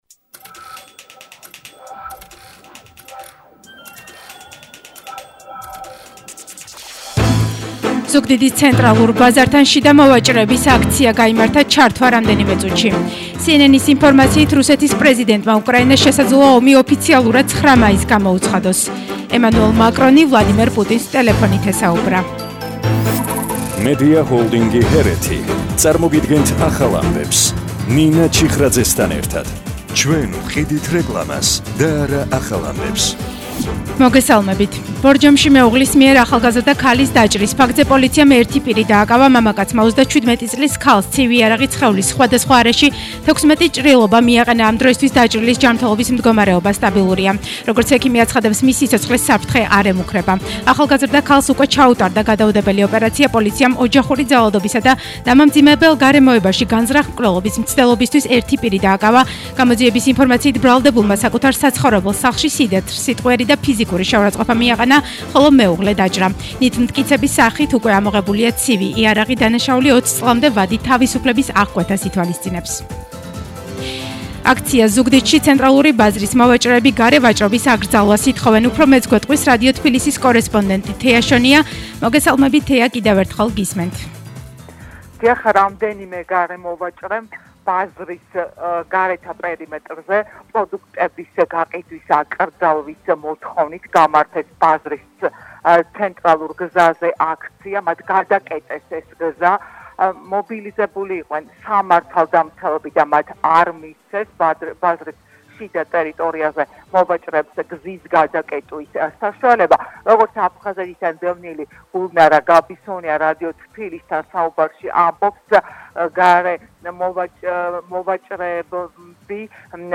ახალი ამბები 17:00 საათზე – 03/05/22